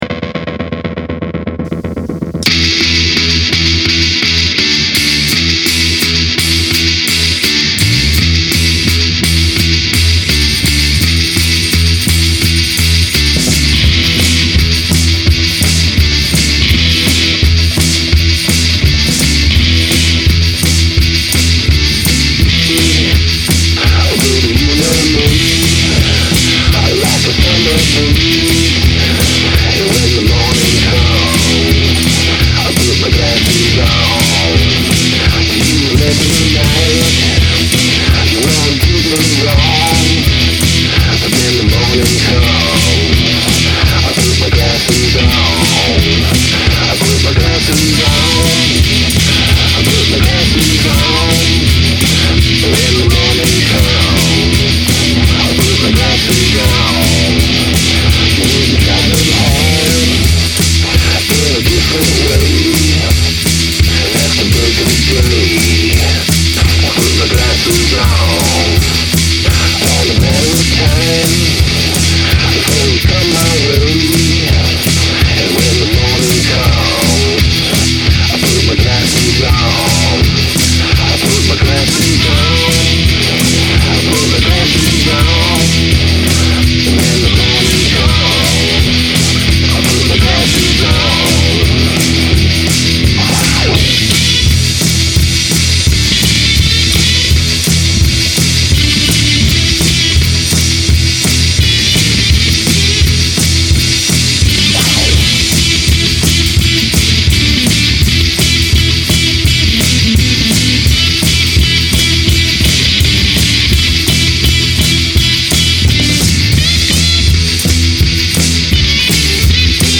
All out blues jam.  One layer at a time.